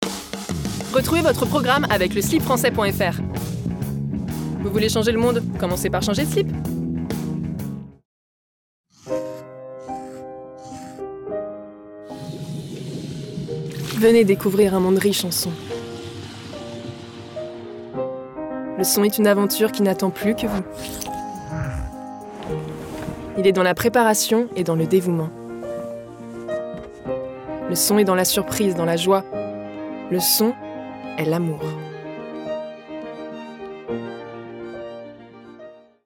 Bandes-son
VoixOff PUB+CORPO